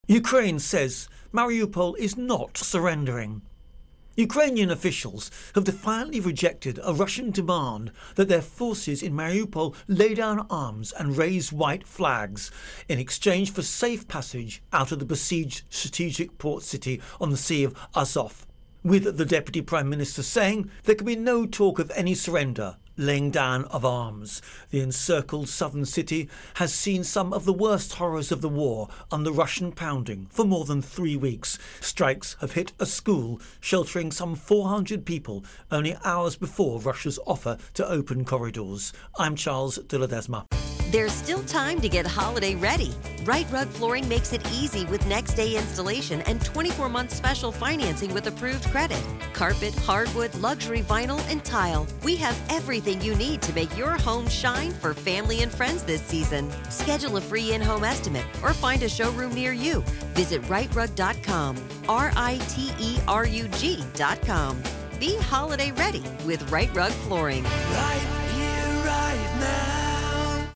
Russia-Ukraine-War-Mariupol Intro and Voicer